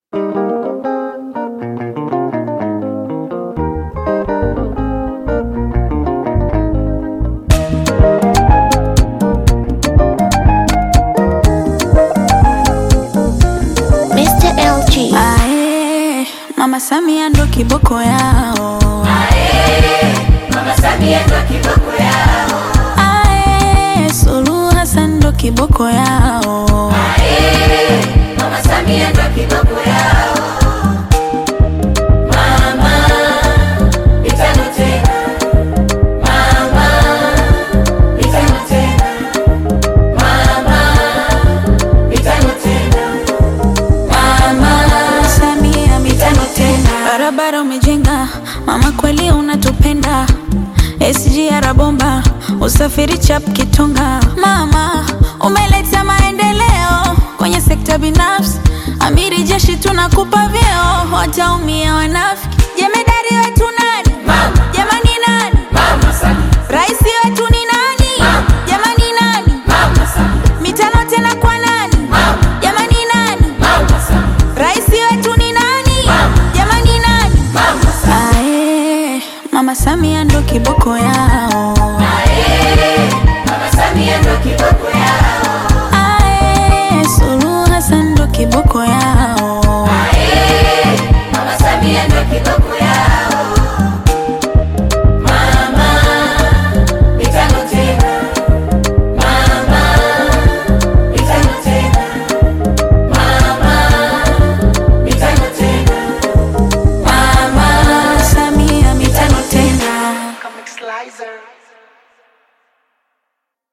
Bongo Flava
This catchy new song